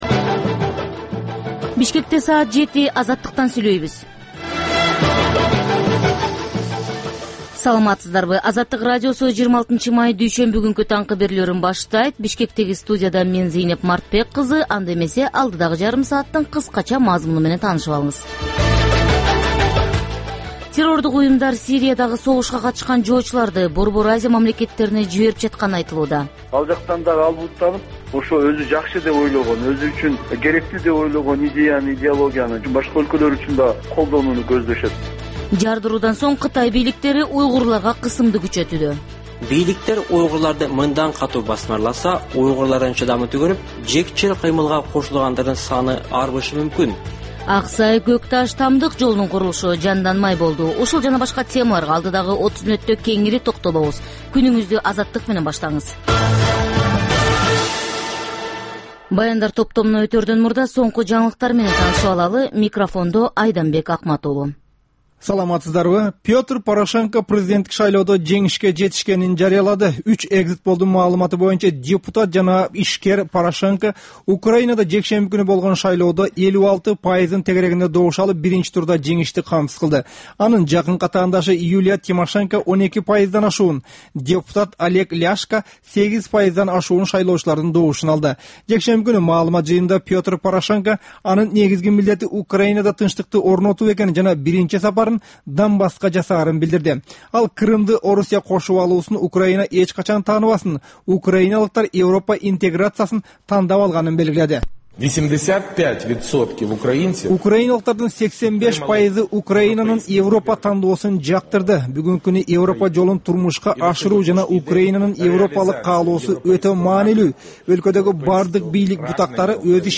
Бул таңкы үналгы берүү жергиликтүү жана эл аралык кабарлар, ар кыл орчун окуялар тууралуу репортаж, маек, күндөлүк басма сөзгө баяндама, «Арай көз чарай» түрмөгүнүн алкагындагы тегерек үстөл баарлашуусу, талкуу, аналитикалык баян, сереп жана башка берүүлөрдөн турат. "Азаттык үналгысынын" бул берүүсү Бишкек убакыты боюнча саат 07:00ден 08:00ге чейин обого чыгарылат.